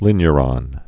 (lĭnyə-rŏn)